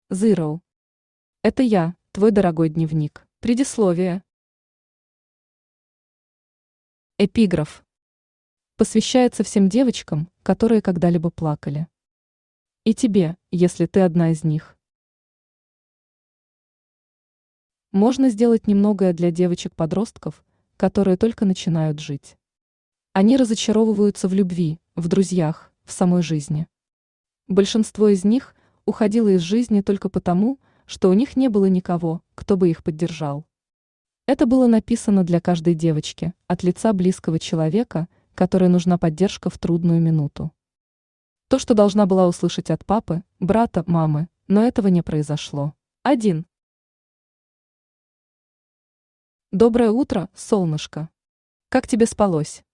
Аудиокнига Это я, твой дорогой дневник…
Автор Zero Читает аудиокнигу Авточтец ЛитРес.